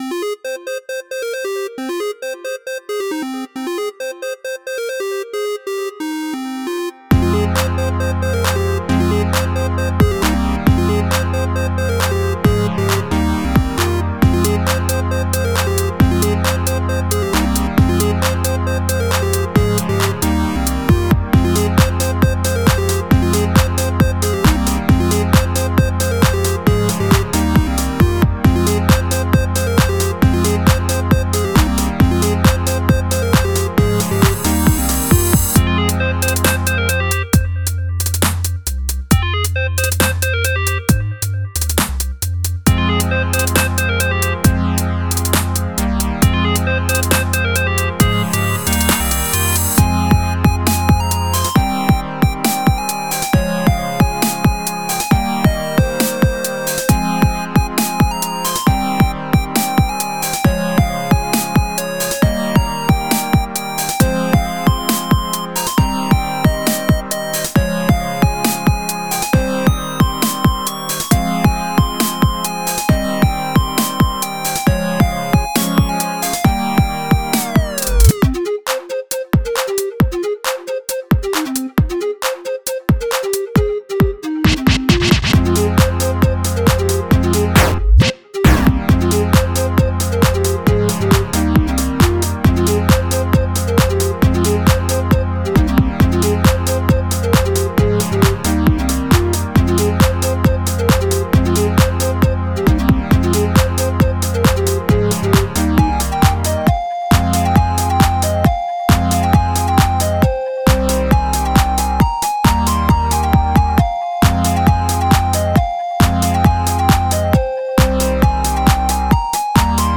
hola! tengo esto, la preview de la segunda canción que hago... más bien una instrumental. sí, segunda. creo que no mostré la primera pero... bueno olviden eso. esta es la primera electro (más o menos) que hago, y quería escuchar opiniones. no está terminada, según yo aún puedo agregarle o corregirle cosas, pero por ser la primera no sé muy bien qué hacerle así que la dejo por aquí a ver que me dicen ustedes.
Bueno tú de la canción electrónica, me gustó si.
Aunque en un punto el sonido no sé cómo se llama me causó un poco de dolor de cabeza, como que quedó mucho en un mismo ritmo sin cambios y ajá.